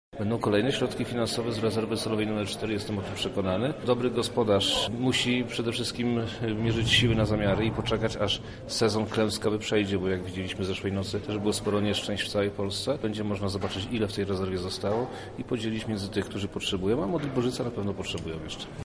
Za otrzymany milion gmina będzie mogła odbudować prawie dwa kilometry dróg. To nie jest jeszcze nasze ostatnie słowo – mówi wojewoda lubelski Przemysław Czarnek: